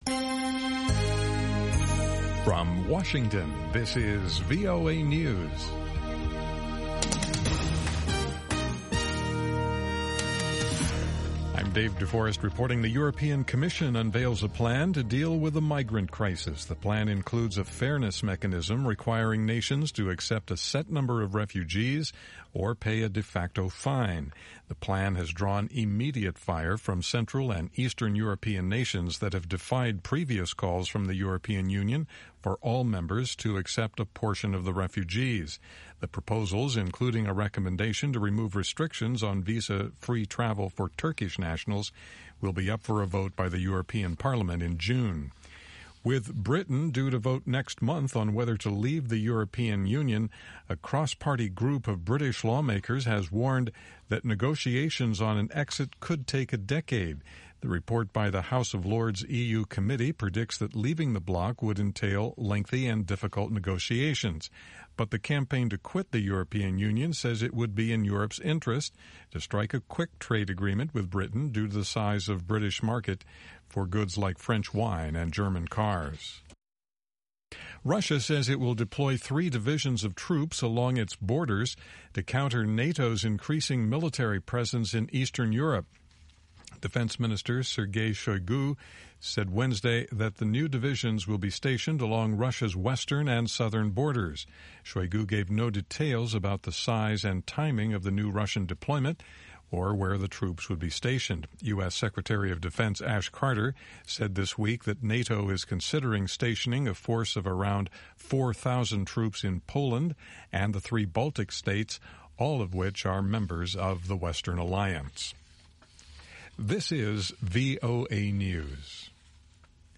1900 UTC Hourly Newscast for May 4, 2016